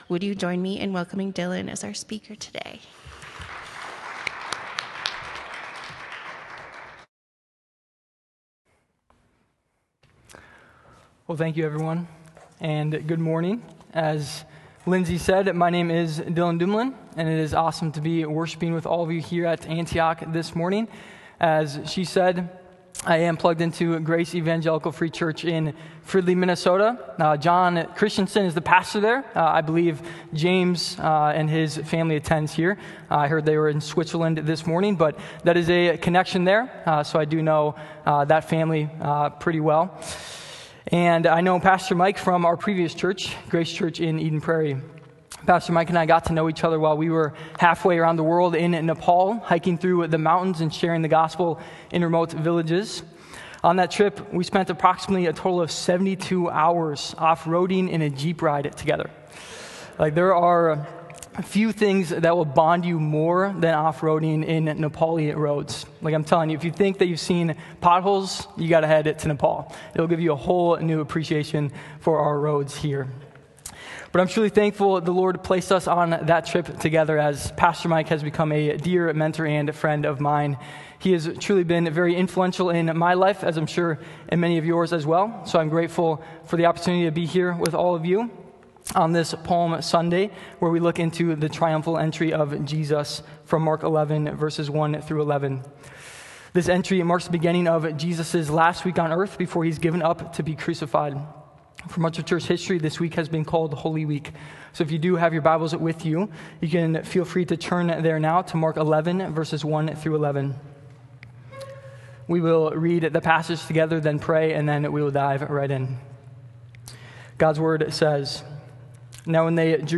sermon-mark-the-anticlimactic-entry.m4a